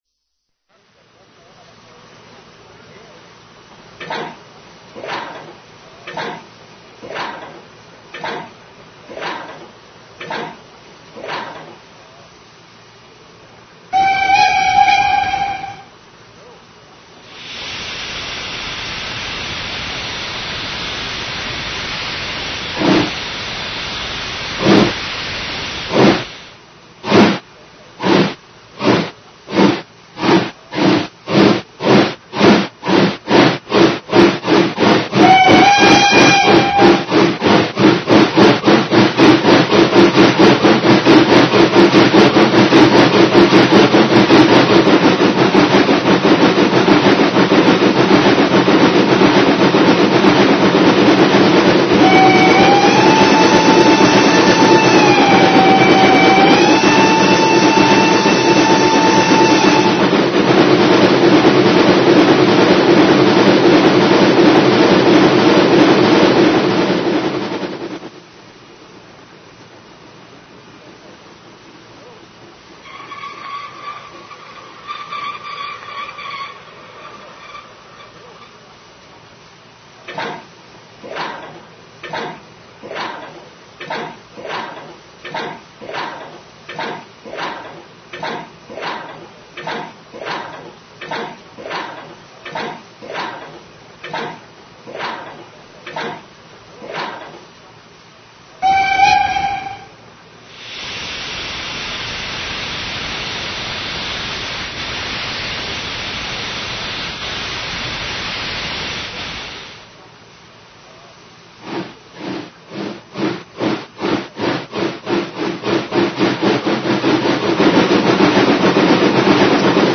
Nejnovější řada dekodérů ZIMO řady MS s 16bitovým zvukem a dalšími vylepšeními.
zvuková ukázka popis přiřazení funkčních tlačítek